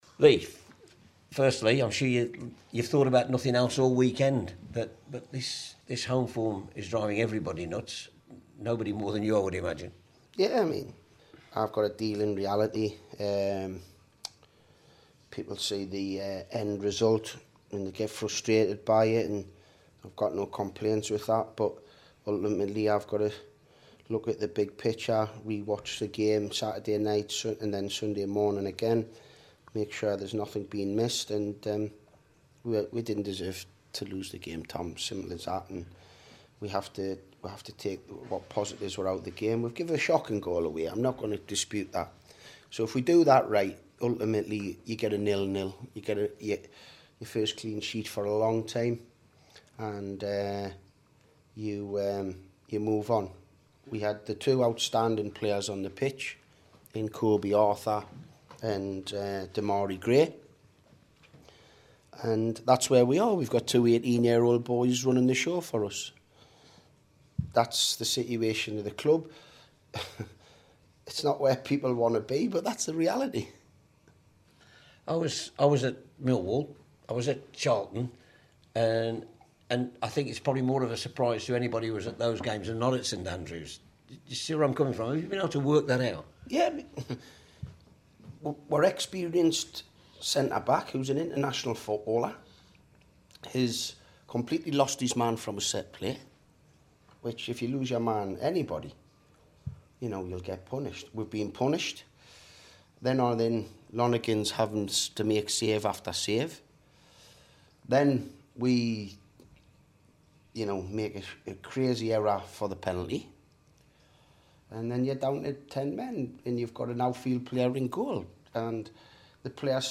Lee Clark's final interview as Blues boss